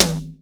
TOM     1A.wav